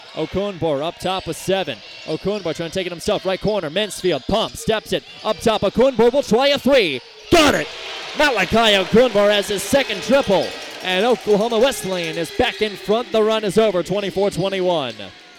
Coverage can be heard on Sports Talk, 99.1 FM - KPGM.